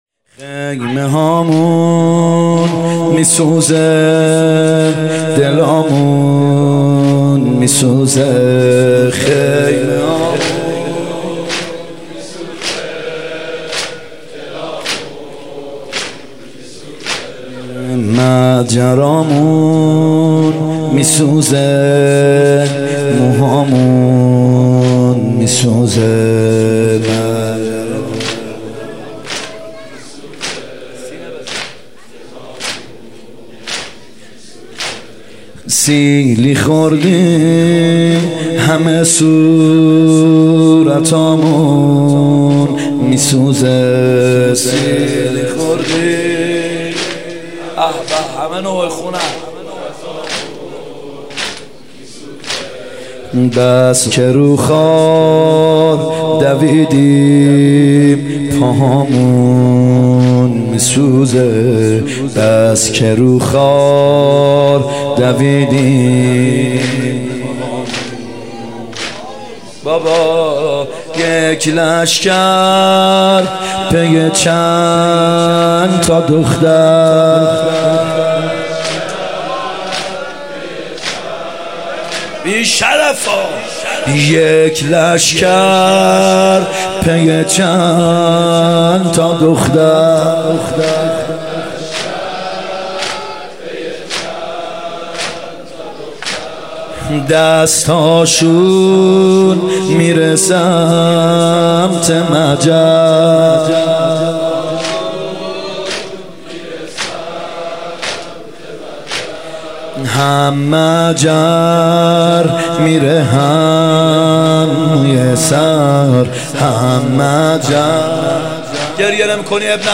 محرم 95(هیات یا مهدی عج)